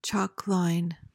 PRONUNCIATION: (CHAWK lyn) MEANING: noun: 1.